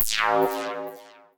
tekTTE63038acid-A.wav